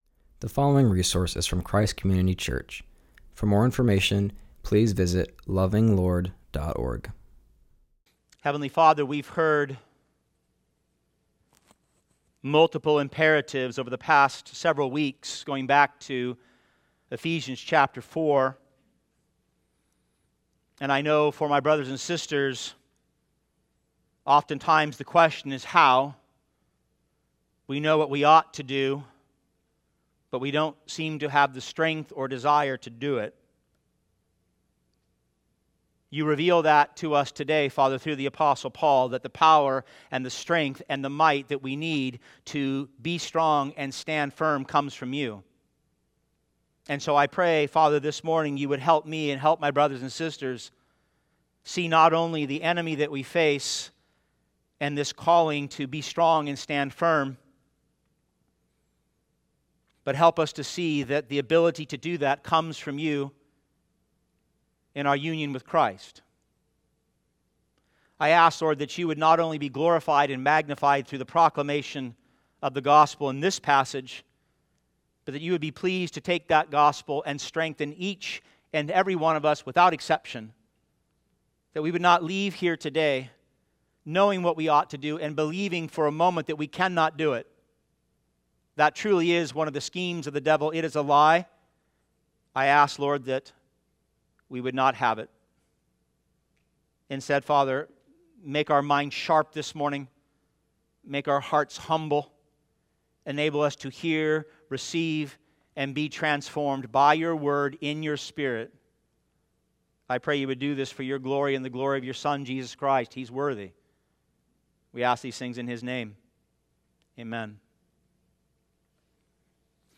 preaches from Ephesians 6:10-20.